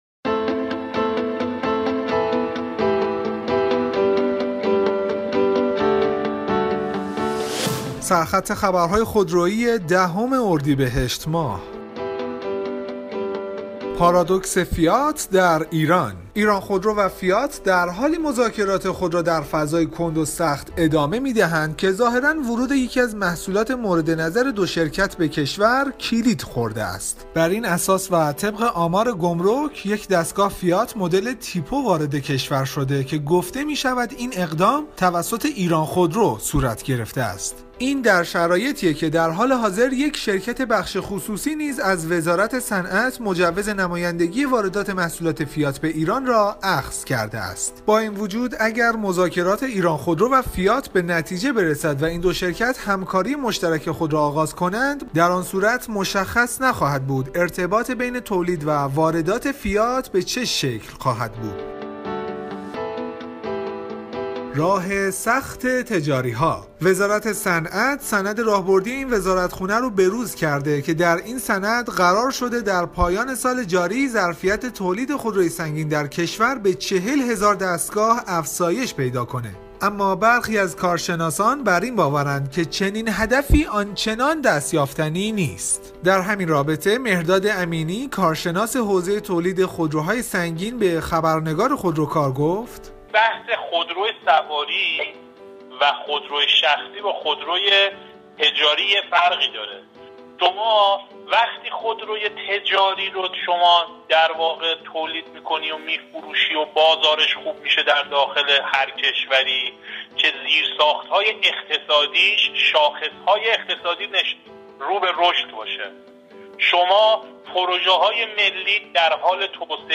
برچسب ها: خودرو ، خودروکار ، بسته صوتی ، بسته صوتی اخبار ، سرخط خبرهای خودرویی ، اخبار خودرویی ، فایل صوتی